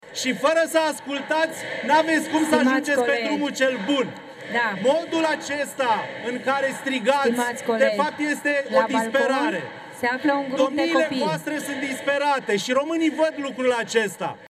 „Stimați colegi, la balcon se află un grup de copii”, spune președinta ședinței din Parlament.